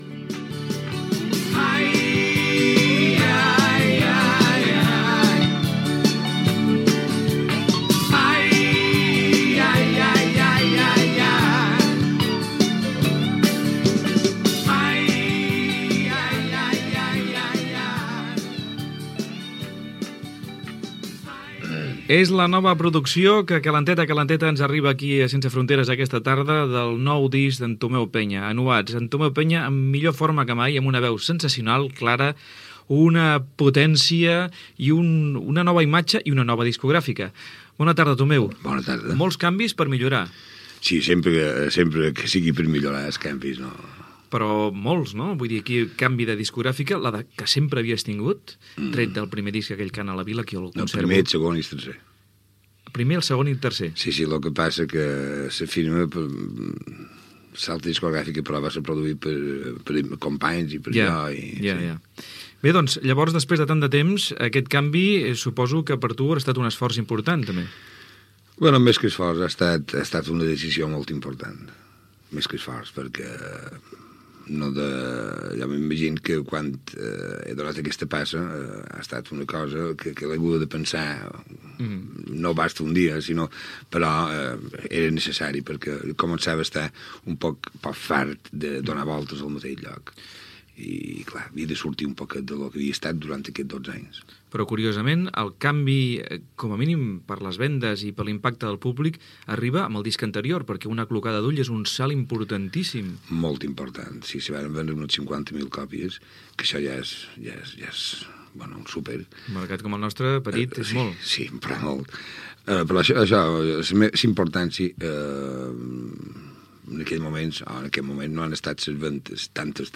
Entrevista al cantant Tomeu Penya que presenta el disc "Anuats"